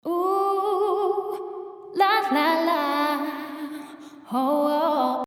次にToneの項目ですが、これはリバーヴの雰囲気を設定することが出来ます。
Cleanは澄んでいるかのような感じで、Darkはその名の通り暗い印象といった感じです。
ToneがDarkの場合